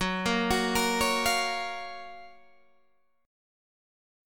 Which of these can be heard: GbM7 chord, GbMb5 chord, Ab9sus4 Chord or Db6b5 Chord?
GbM7 chord